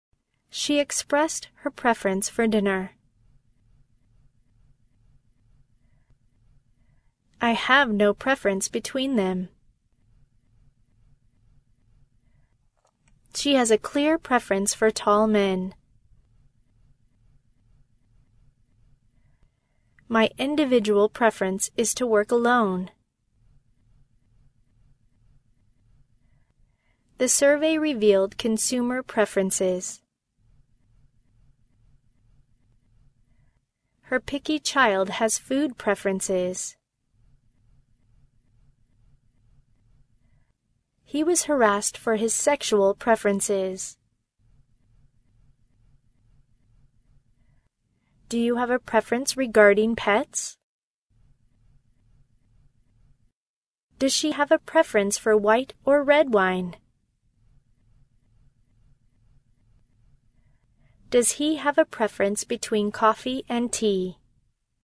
preference-pause.mp3